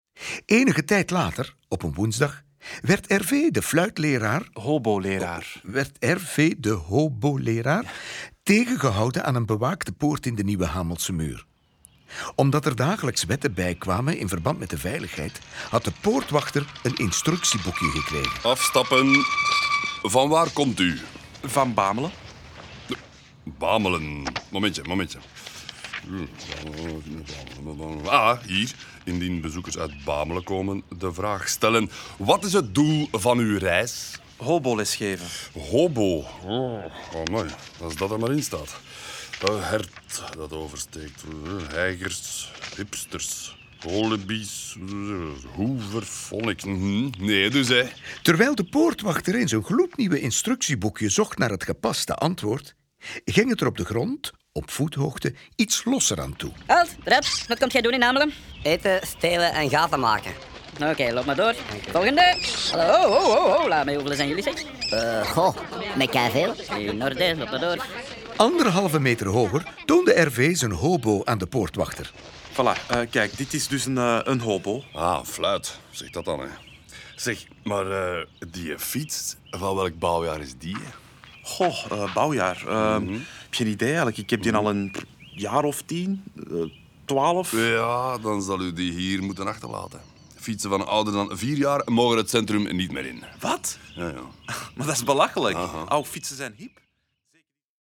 Ze worden verteld, gespeeld en gezongen door Vlaanderens meest getalenteerde acteurs en zitten boordevol humor, liedjes en knotsgekke geluidseffecten.